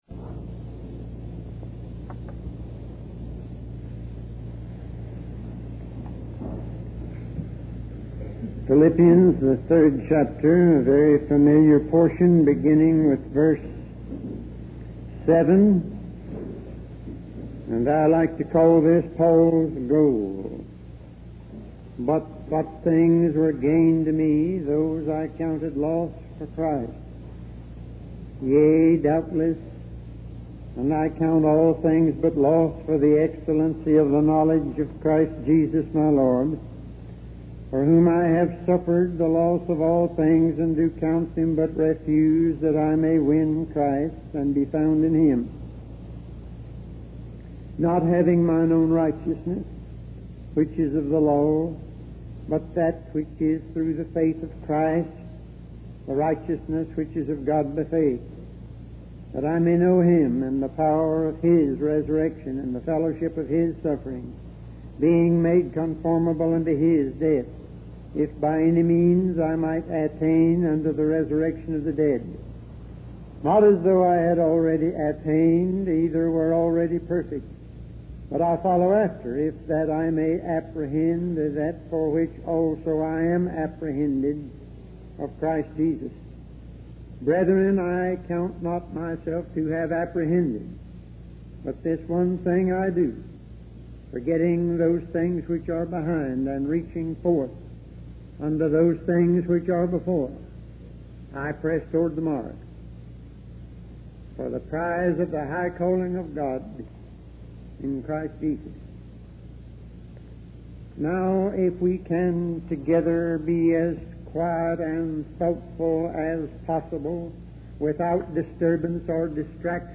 In this sermon, the speaker emphasizes the importance of making choices in life between the bad, the good, and the best. He highlights the danger of settling for the good when we could have had the best. The speaker also warns against complacency and the need for revival, even among good people.